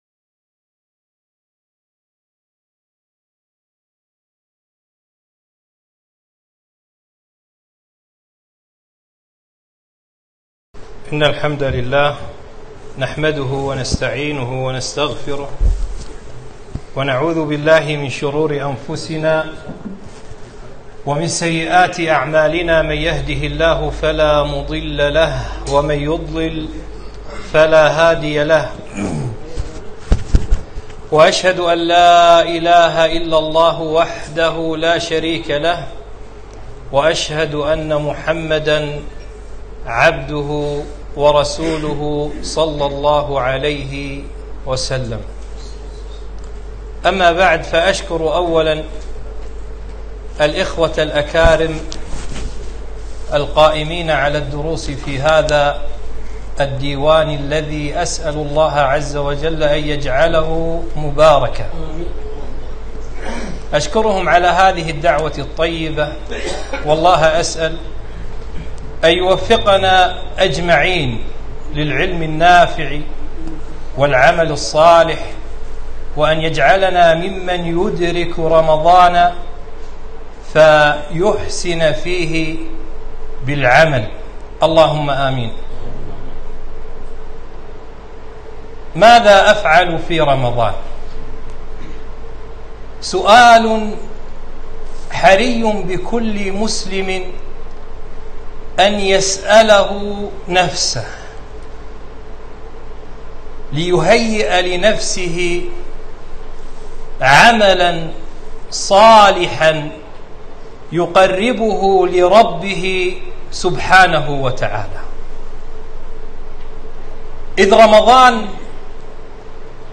محاضرة - ماذا أفعل في رمضان ؟